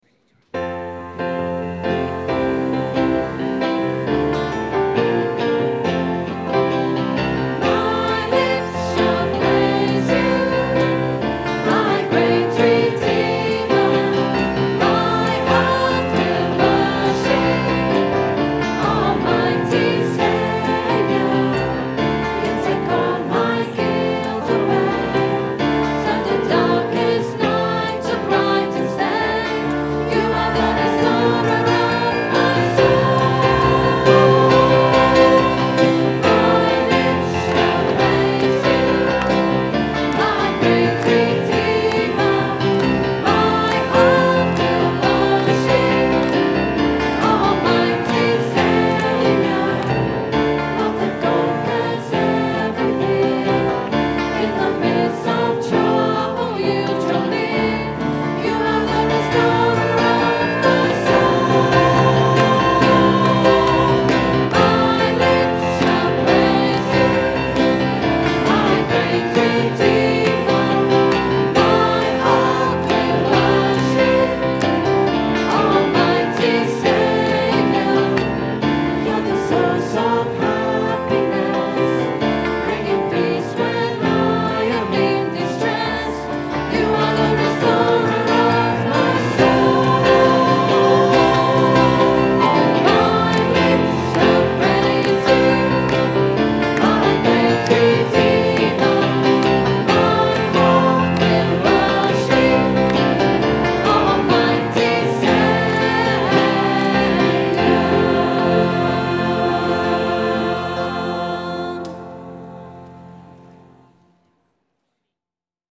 Recorded on a Zoom H4 digital stereo recorder at 10am Mass Sunday 27th June 2010.